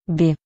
Ääntäminen
US : IPA : [bi]